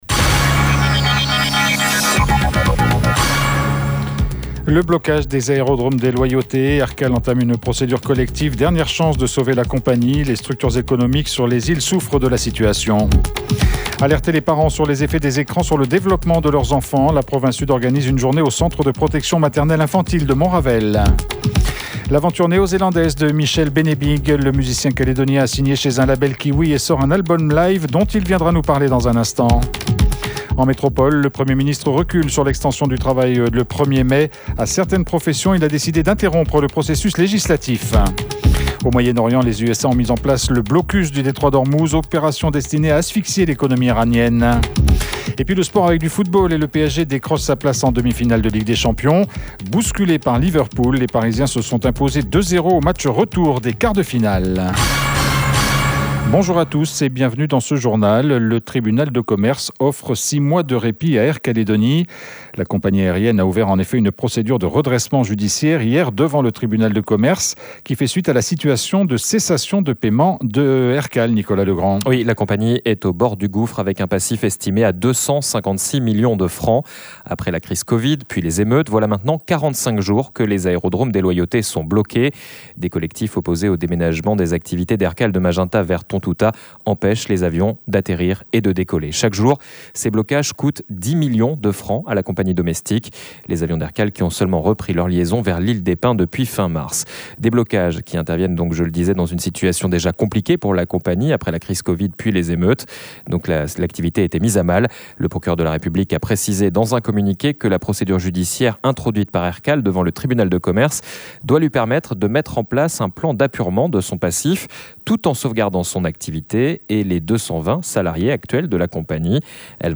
La procédure de redressement judiciaire entamé par Aircal auprès du tribunal de commerce est la dernière chance de sauver la compagnie. C’est ce que nous a dit le procureur de la République qui était notre invité ce matin. Le blocage des aérodromes dans les iles pèse évidement sur la trésorerie de la compagnie et menace sa pérénité.